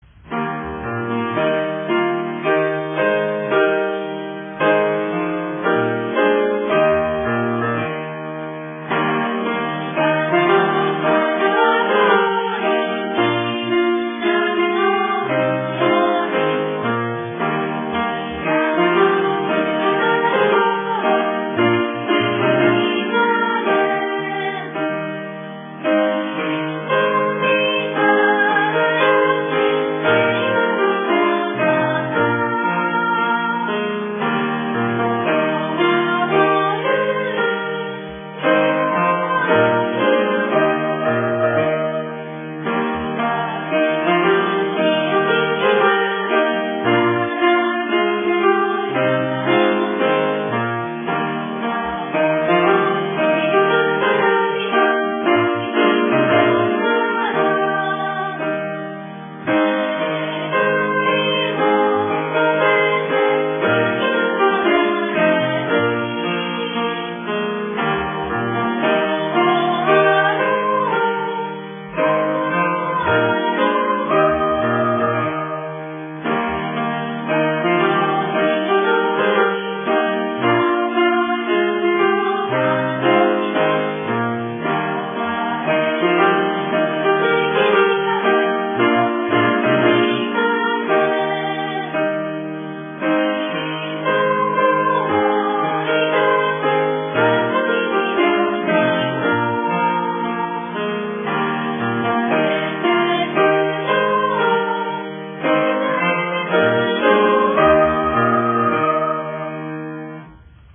愛媛県立宇和高等学校三瓶分校　分校歌（愛媛県立三甁高等学校々歌）